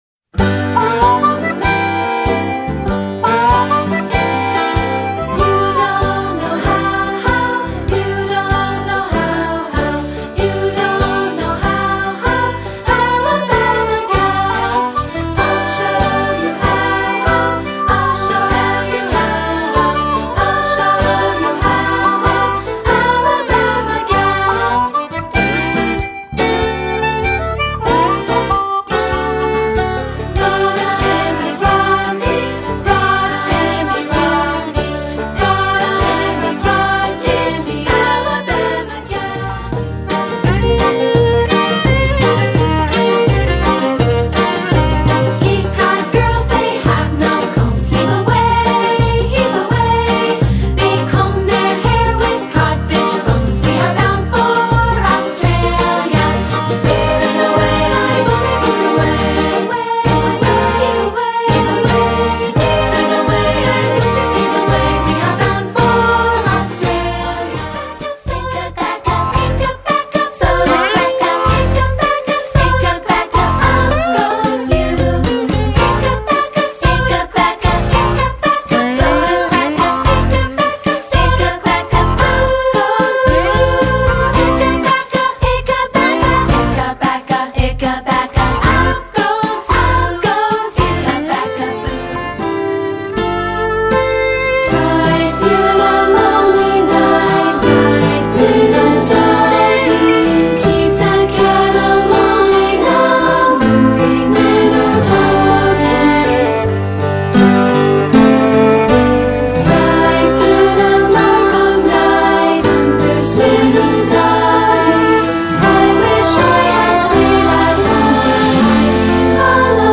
Add a simple ostinato or partner melody for instant harmony.